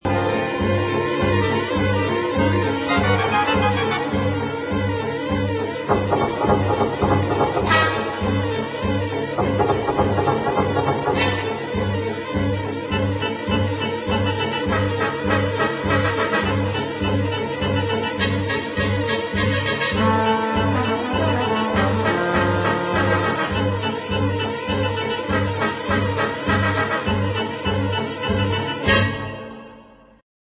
1962 thrilling fast instr.